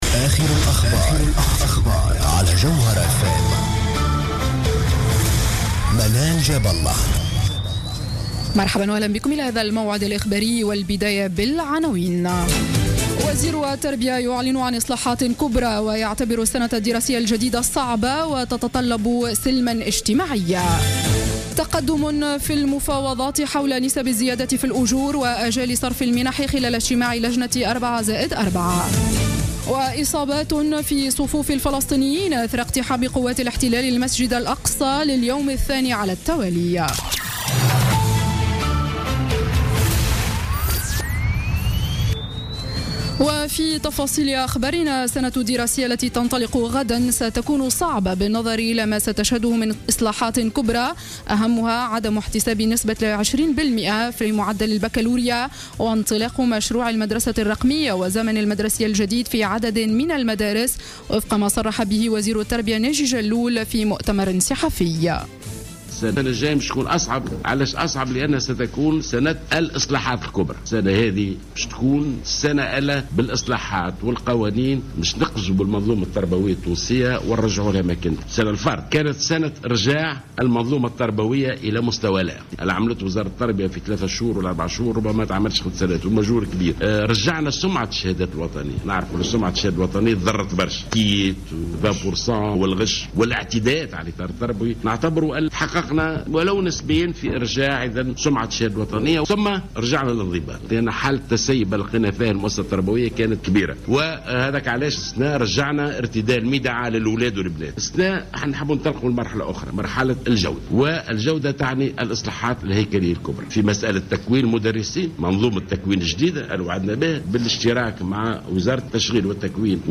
نشرة أخبار السابعة مساء ليوم الاثنين 14 سبتمبر 2015